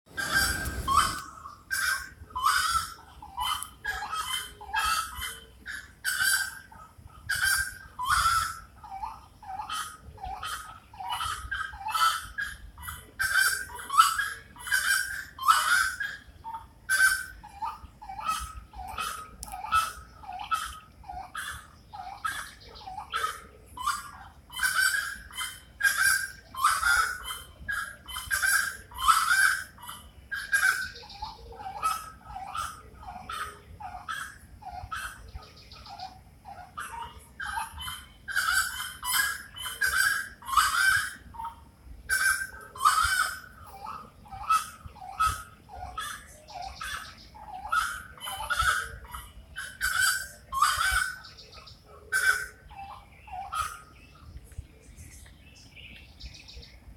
Saracura-três-potes (Aramides cajaneus)
Nome em Inglês: Grey-cowled Wood Rail
Fase da vida: Adulto
Detalhada localização: Eco Área Avellaneda
Condição: Selvagem
Certeza: Fotografado, Gravado Vocal